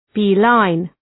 {‘bi:,laın}